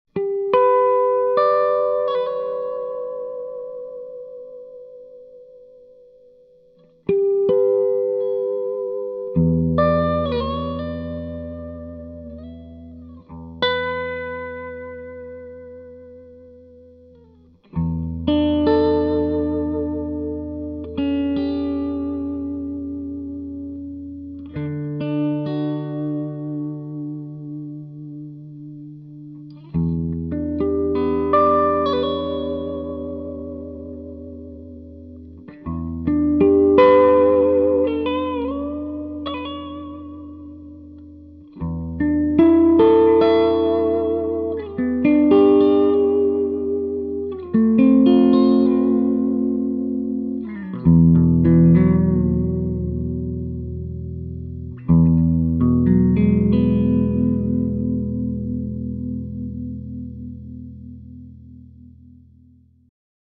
With the EL-34s, the SST-30 takes on a more British tone without losing the richness of the midrange.
Gibson SG - Clean           1 :03